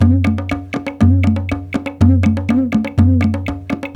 120 -CONG02L.wav